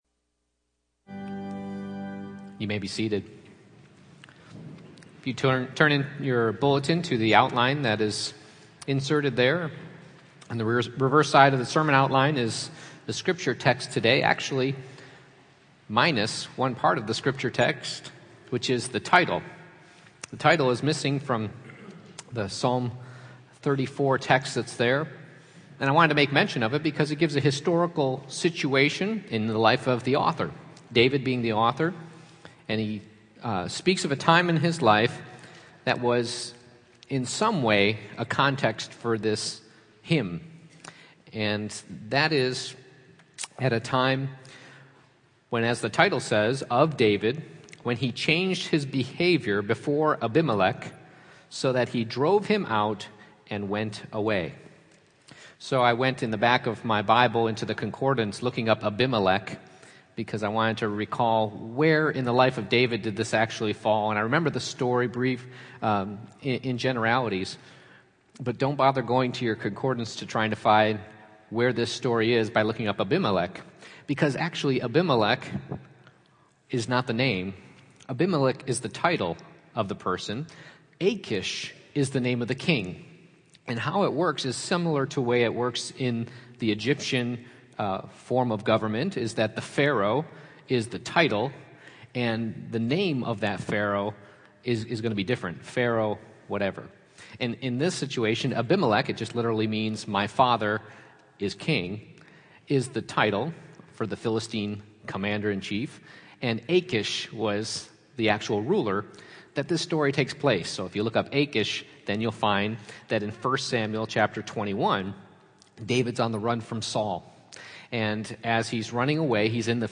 Passage: Psalm 34:1-22 Service Type: Morning Worship